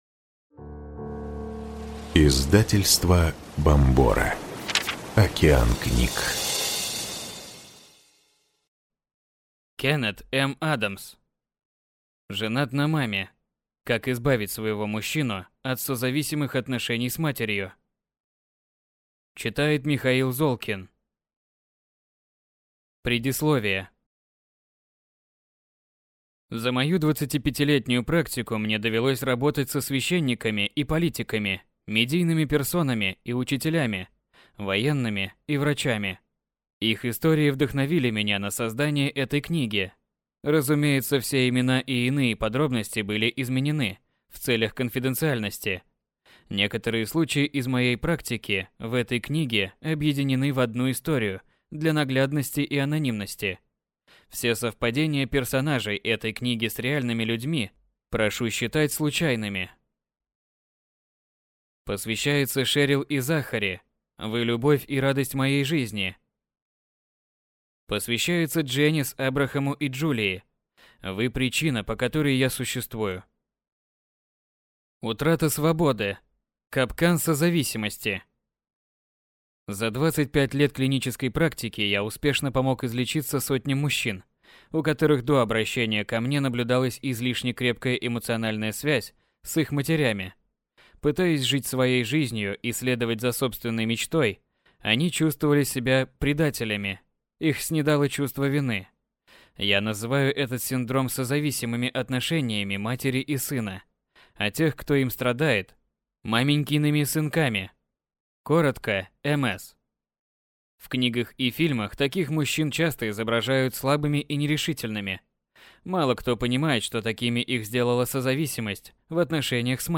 Аудиокнига Женат на маме. Как избавить своего мужчину от созависимых отношений с матерью | Библиотека аудиокниг